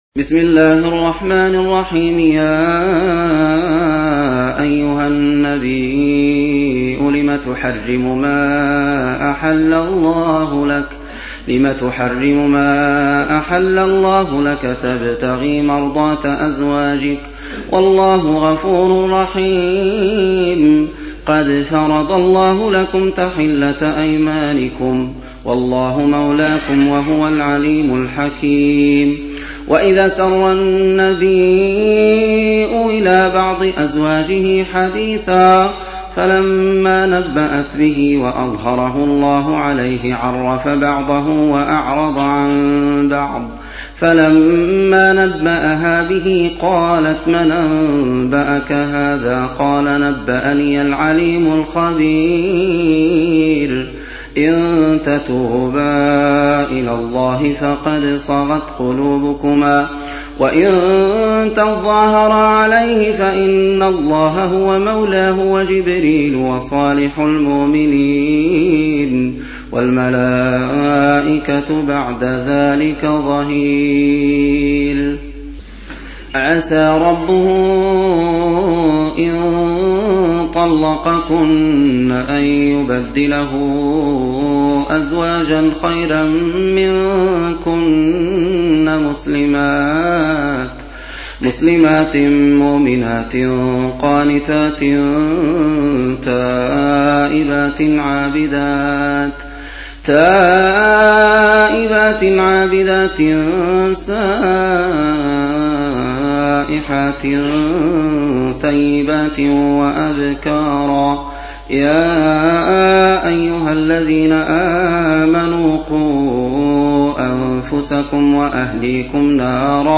Surah Sequence تتابع السورة Download Surah حمّل السورة Reciting Murattalah Audio for 66. Surah At-Tahr�m سورة التحريم N.B *Surah Includes Al-Basmalah Reciters Sequents تتابع التلاوات Reciters Repeats تكرار التلاوات